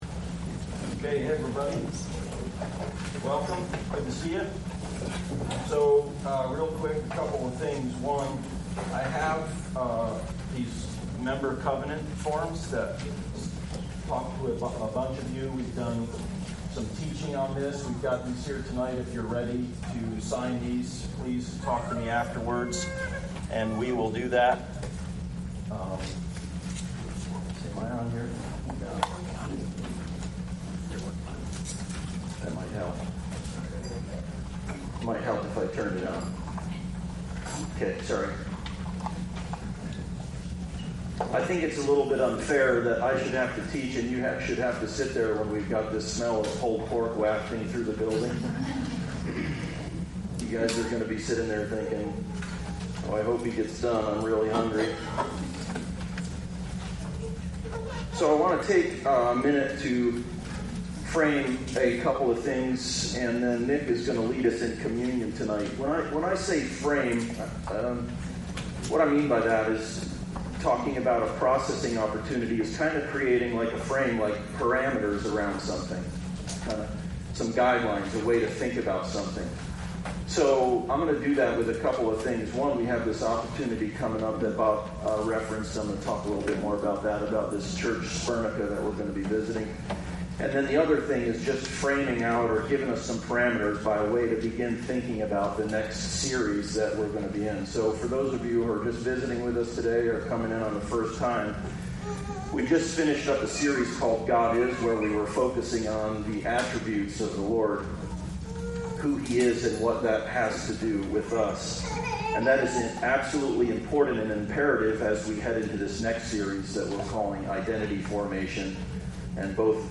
Identity Formation Passage: Ephesains 1:1 Service Type: Sunday Service Download Files Bulletin « God is…